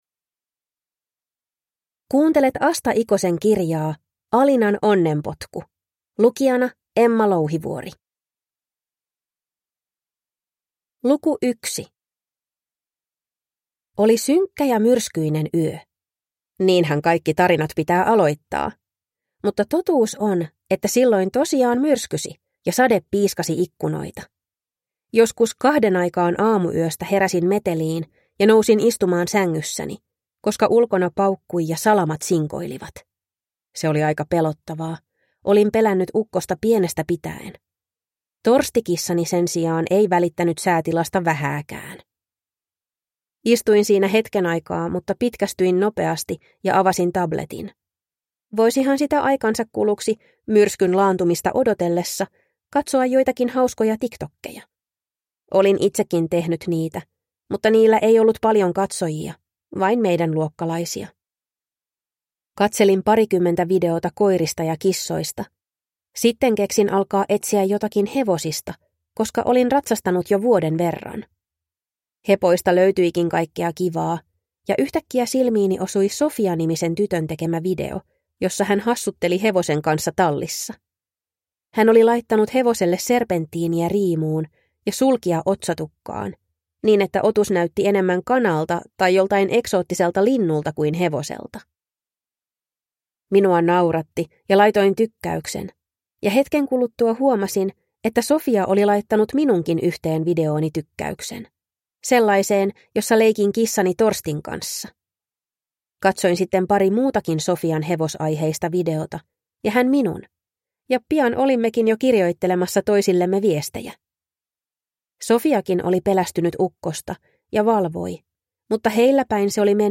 Alinan onnenpotku – Ljudbok